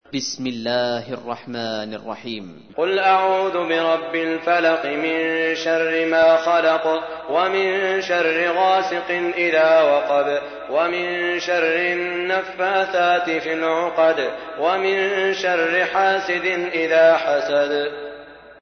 تحميل : 113. سورة الفلق / القارئ سعود الشريم / القرآن الكريم / موقع يا حسين